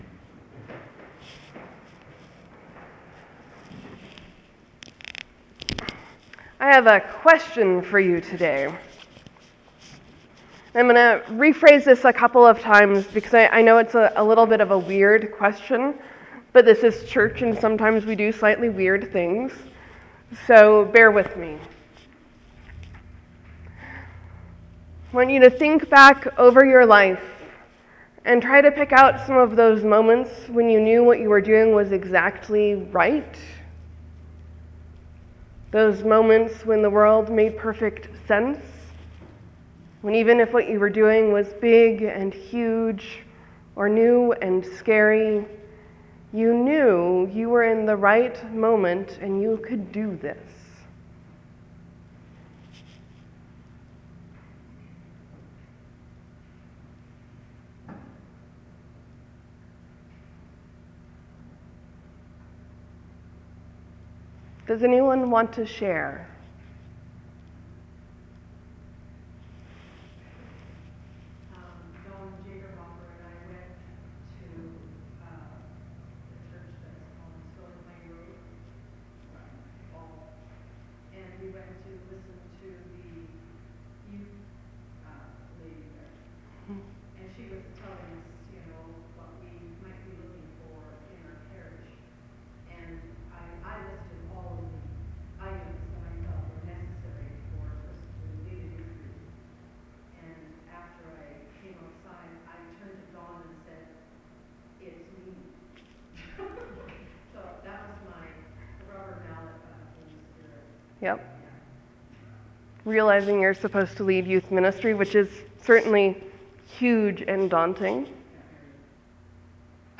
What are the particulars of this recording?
Morsels & Stories: I asked people to reflect on the times in their life when everything was just right, even if it was just half a second. There will be a bit of silence and some strange background noise as a few people share.